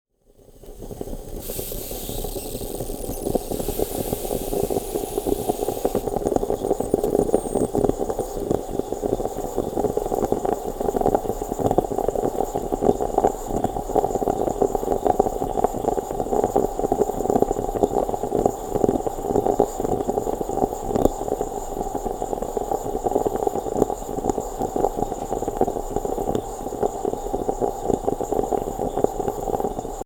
El caso es que pensé que podría ser bonito usar el sonido del café como despertador en el móvil, así que me puse a preparar un café y a grabarlo.
Cuando nuevamente vuelve a bullir el café, la casa cruje. Los ruiditos cotidianos de un vieja casa.
Esto ni parece café ni nada.
Sonido de café para Android 30 seg.
CAFE_-_Sonido.mp3